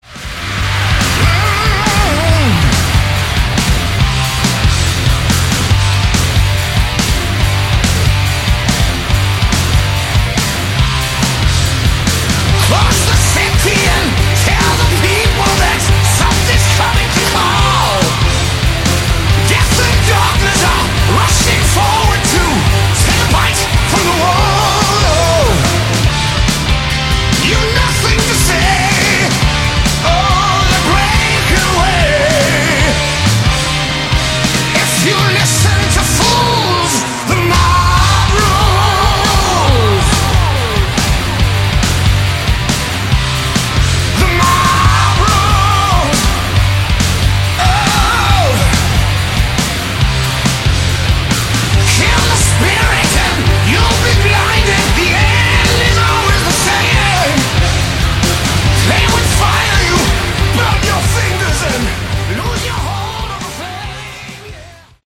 Category: Melodic Metal
vocals
drums
bass
guitars